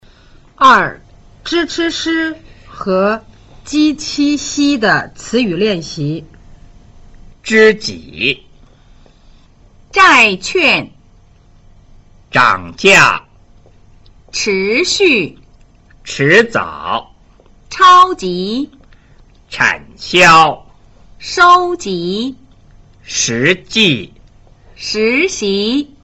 聲母 zh ch sh 翹舌音 和 j q x 舌面音的分辨
2. zh ch sh 和 j q x 的詞語練習